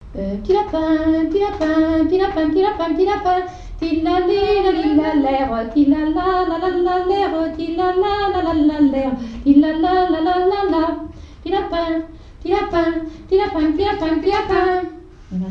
Thème : 1074 - Chants brefs - A danser
danse : polka lapin
airs de danses et chansons traditionnelles
Pièce musicale inédite